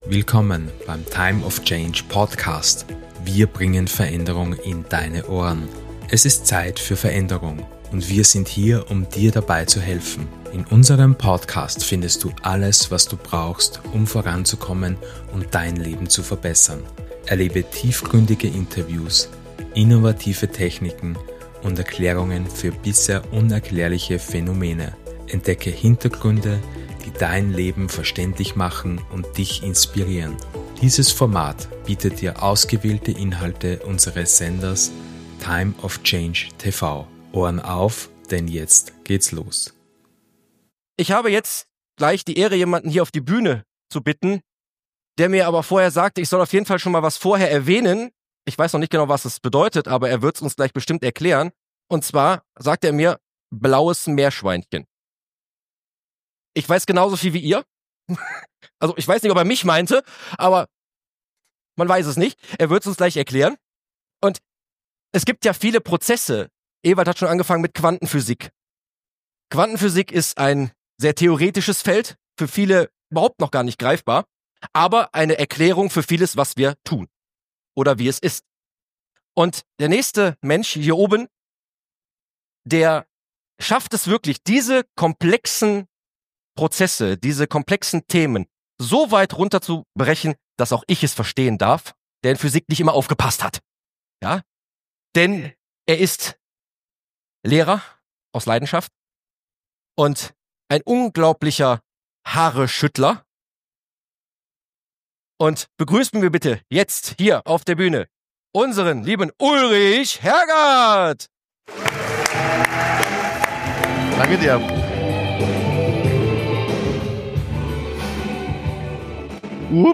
Beschreibung vor 11 Monaten In dieser vierten Stunde des Seminarwochenendes, tauchen wir noch tiefer in die faszinierende Welt der Quantenphysik und ihrer Verbindung zur menschlichen Wahrnehmung ein.
Mit Hilfe eines Rauschgenerators demonstriert er, wie alle Töne in einem Rauschen enthalten sind und wie man durch Filterung einzelne Töne herausziehen kann. Diese Analogie wird auf die menschliche Wahrnehmung übertragen, indem er zeigt, wie wir durch unsere individuellen Filter unsere Realität gestalten können.